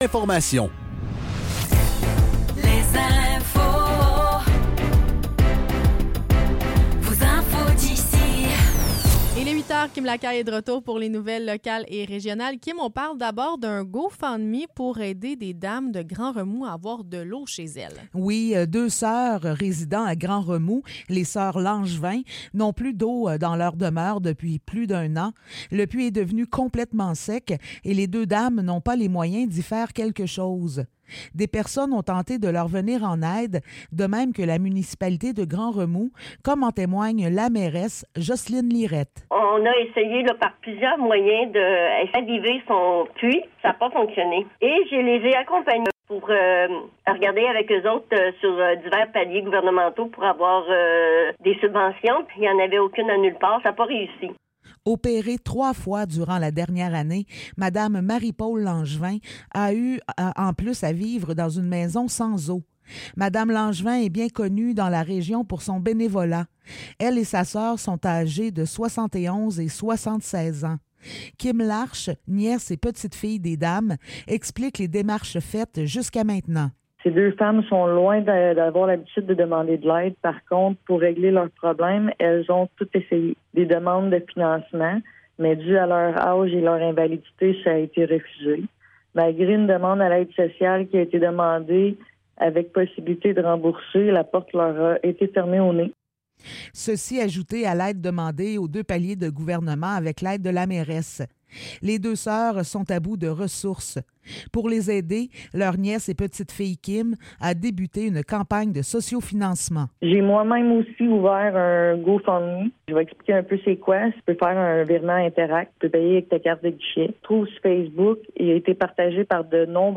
Nouvelles locales - 2 janvier 2024 - 8 h